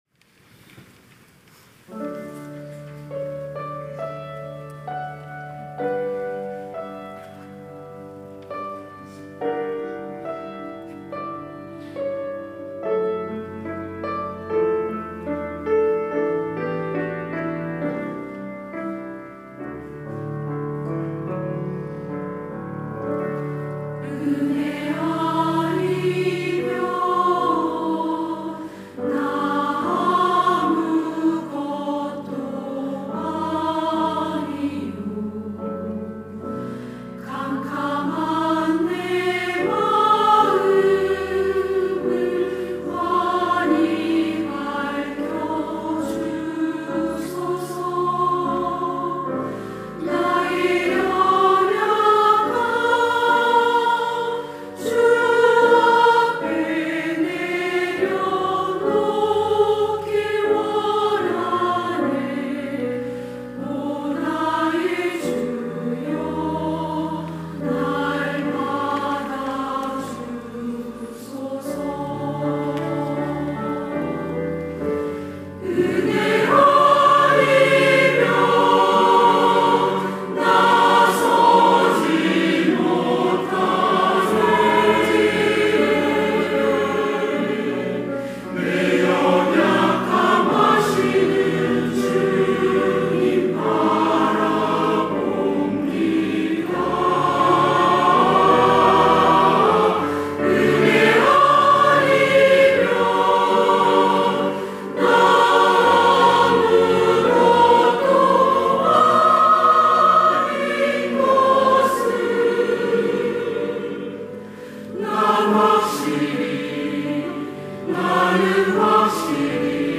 시온(주일1부) - 은혜 아니면
찬양대